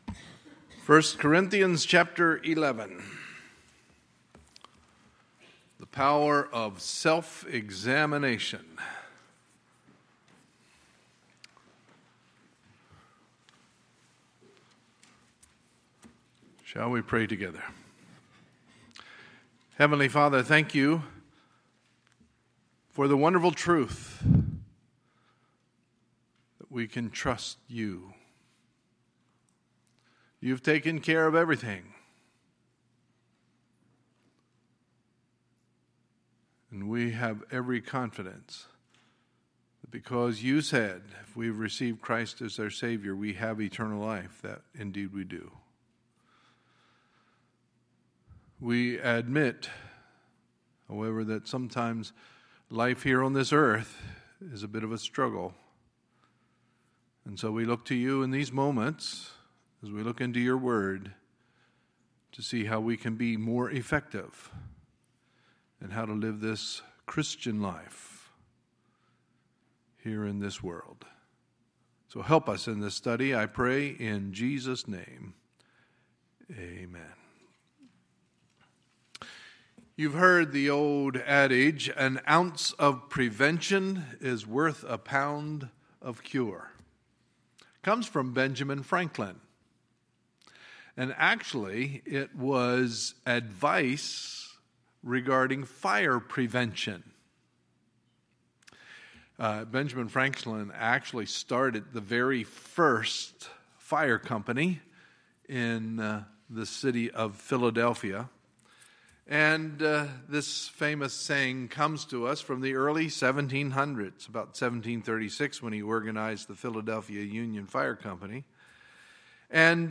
Sunday, April 30, 2017 – Sunday Morning Service